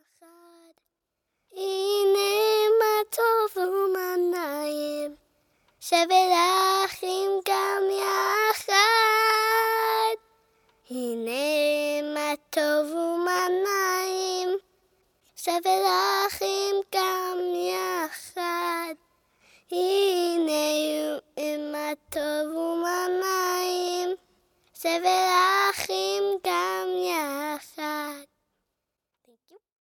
6 youth choirs